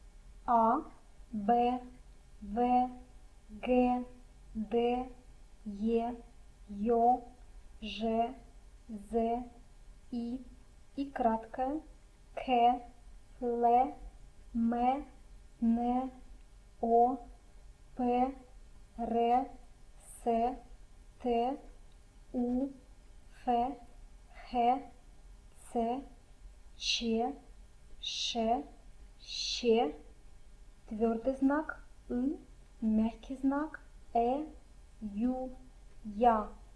RU_alphabet.mp3